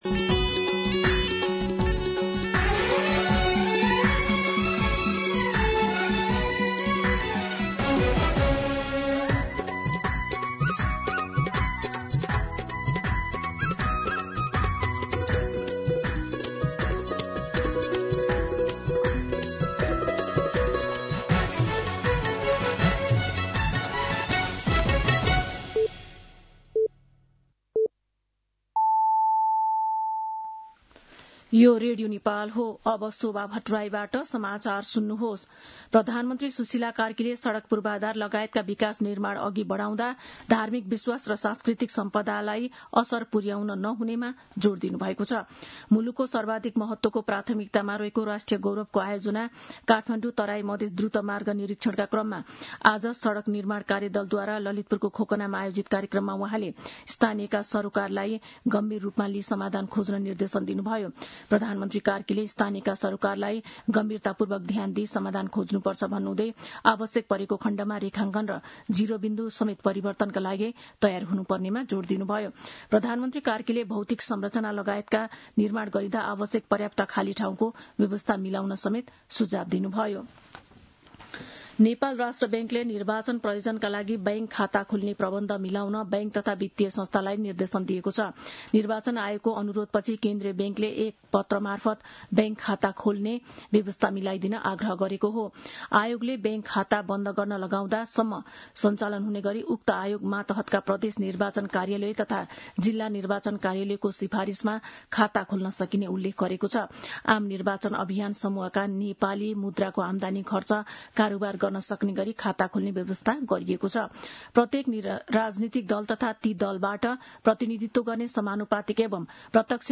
दिउँसो १ बजेको नेपाली समाचार : १३ माघ , २०८२
1pm-News-10-13.mp3